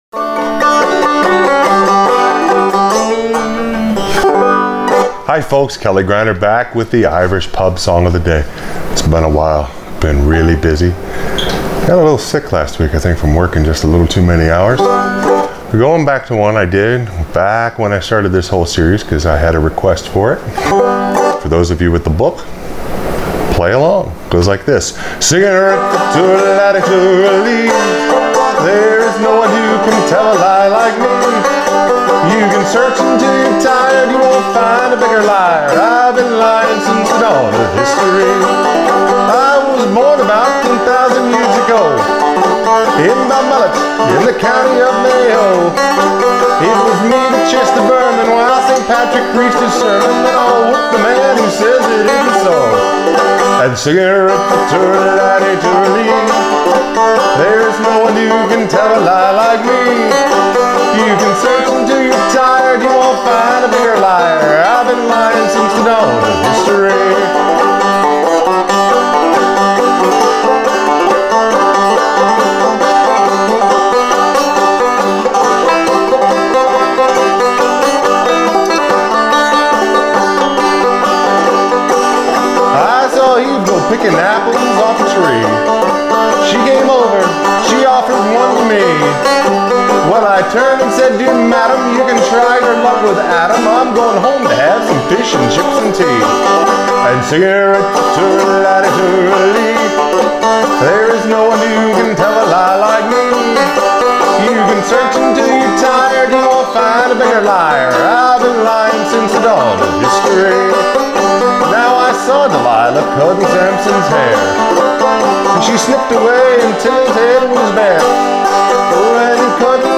Irish Pub Song Of The Day – The Liar on Frailing Banjo
For those of you not familiar with the tune, think of “If You’re Happy And You Know It, Clap Your Hands”.